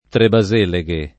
[ treba @% le g e ]